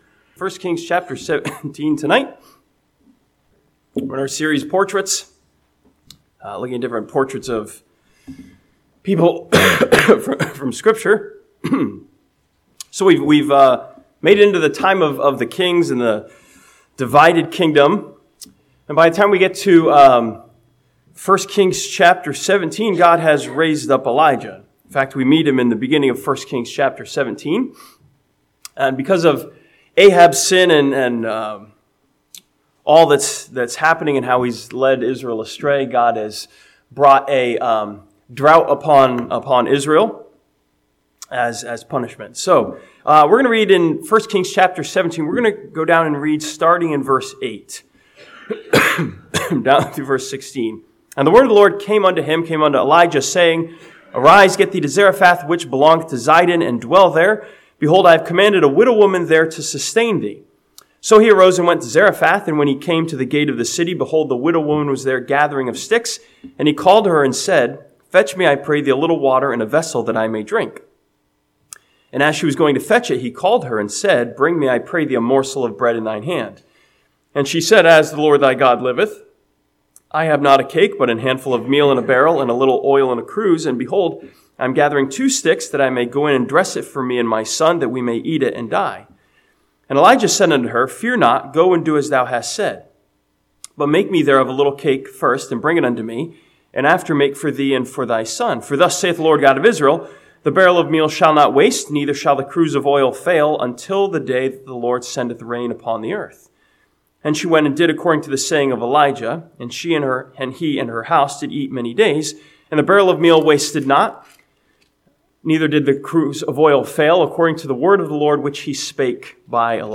This sermon from 1 Kings chapter 17 studies the widow of Zarephath as a portrait of accomplishment through a spiritual choice.